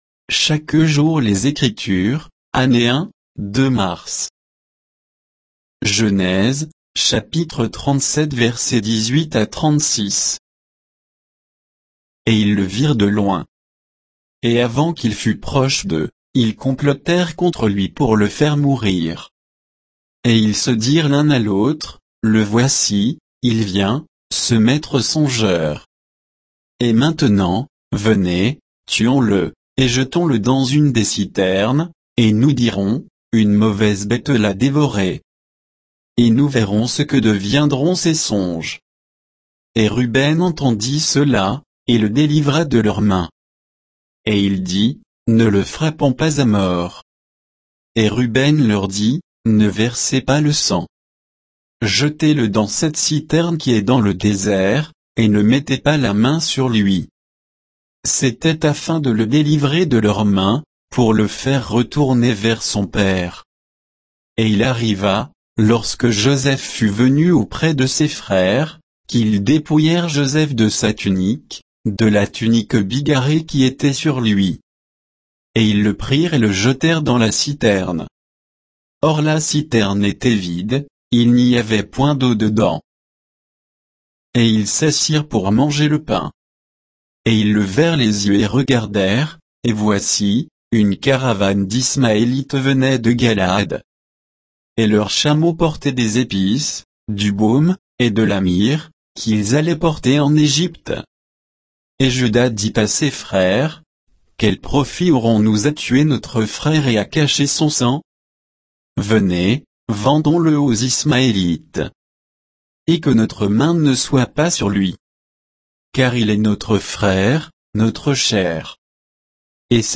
Méditation quoditienne de Chaque jour les Écritures sur Genèse 37